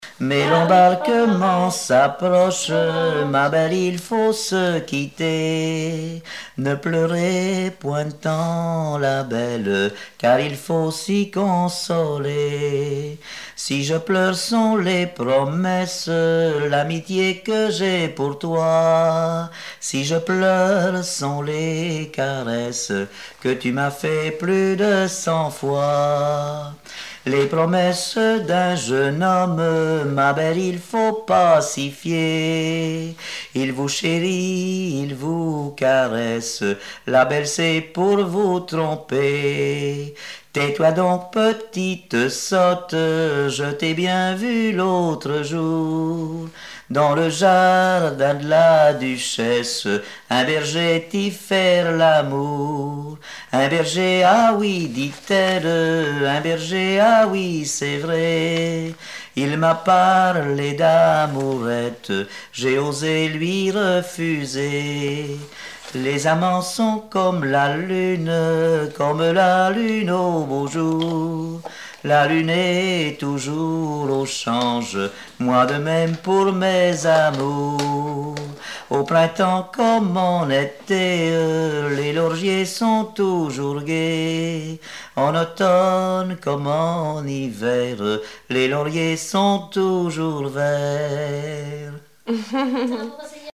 Répertoire de chansons traditionnelles et populaires
Pièce musicale inédite